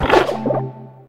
oinkologne_ambient.ogg